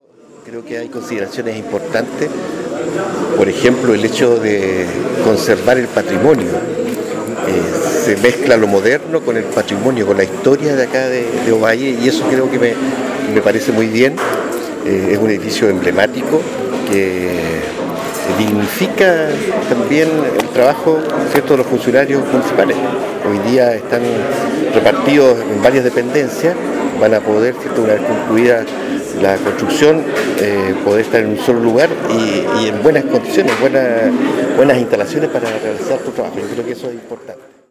El consejero Pedro Valencia, por su parte, valoró que